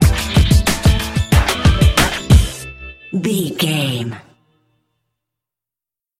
Aeolian/Minor
drum machine
synthesiser
percussion
neo soul
acid jazz
energetic
bouncy